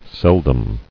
[sel·dom]